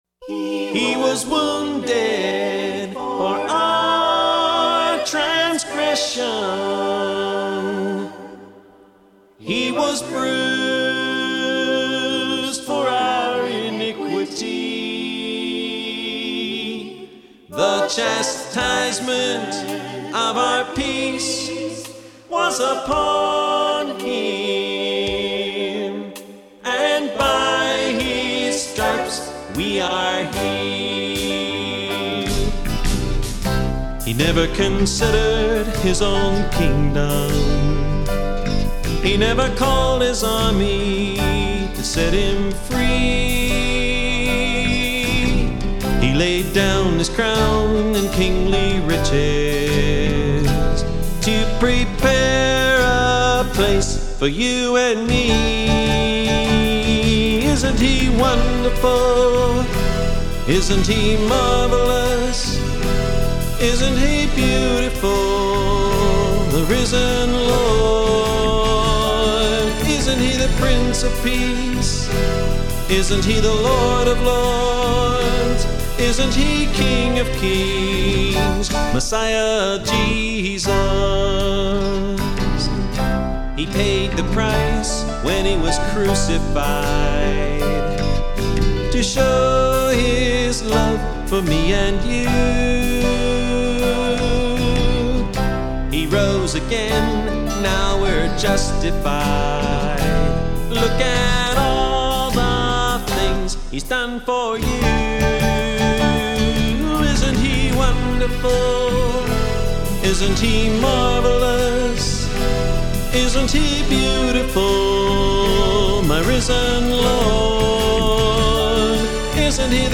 It turned into a beautiful song of worship and praise.
This was the first album that we recorded in our own studio.